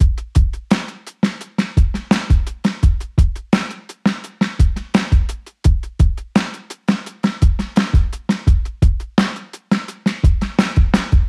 Old School Is Dead（DB-30 OFF / ON）
Old-School-Is-Dead-DRY[663].mp3